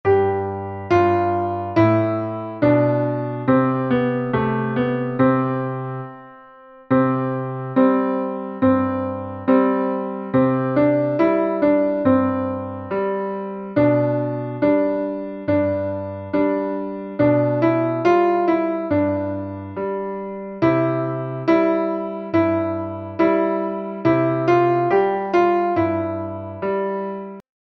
MP3ピアノ音源も付いているので、ダウンロード後すぐに活用いただけます。
ピアノ伴奏譜＆練習用ピアノ音源